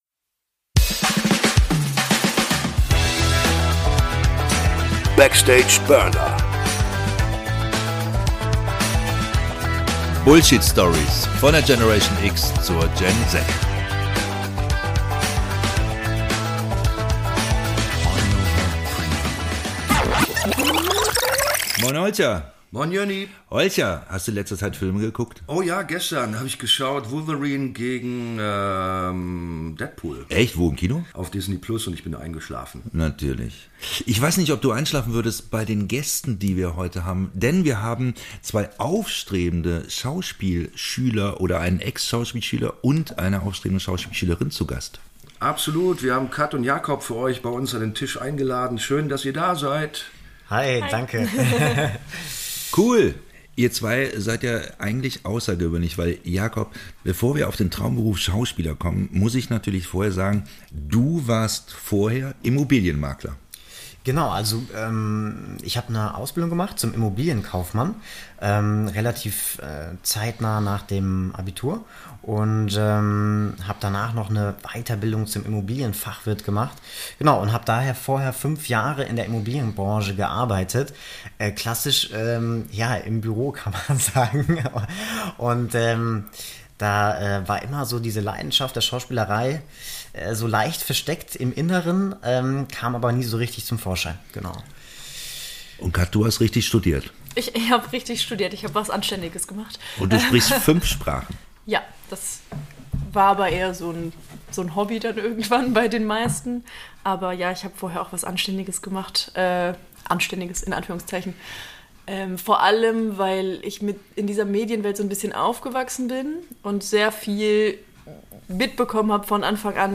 Zwei junge und hoffnungsvolle Schauspielanfänger sprechen mit uns über den Entschluss ihr Leben umzukrempeln.